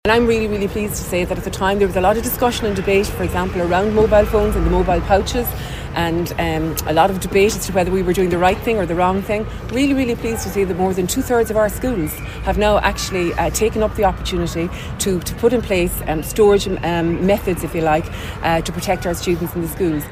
Minister for Children Norma Foley says the majority of schools have opted to use the pouches.